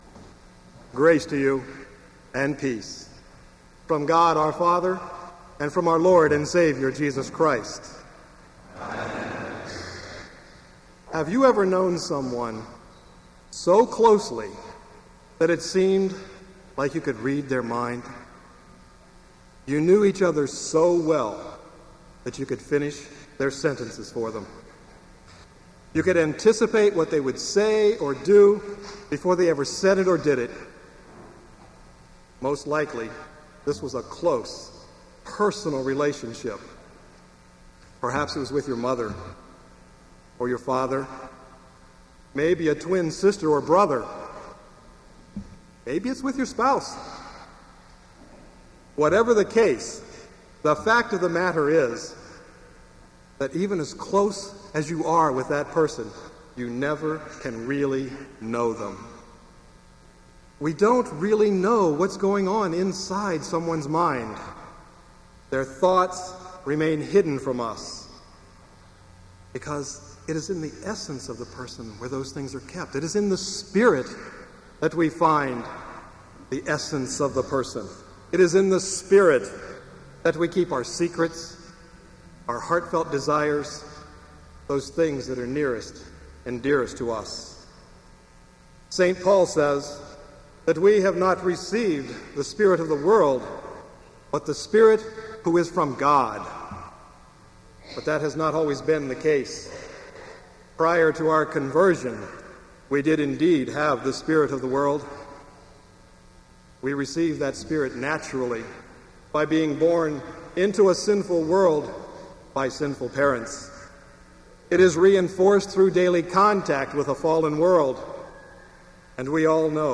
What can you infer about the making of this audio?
Kramer Chapel Sermon - May 11, 2005